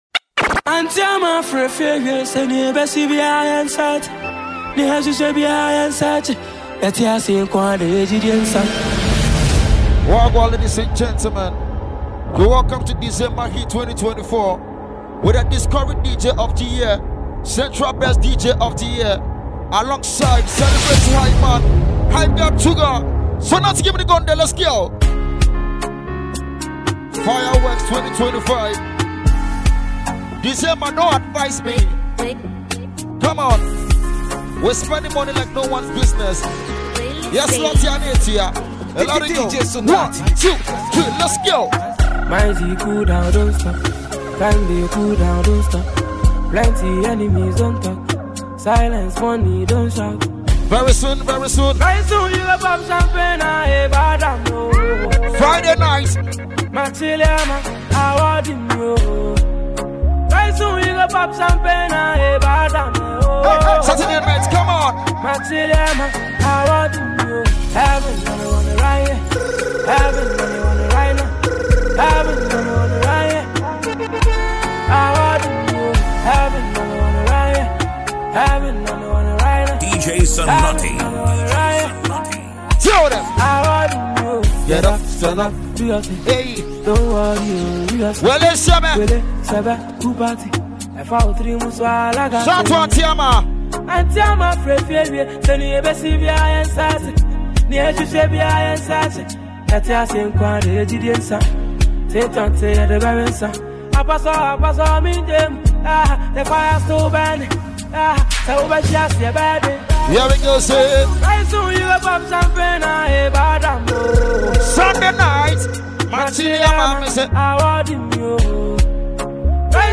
DJ mixtape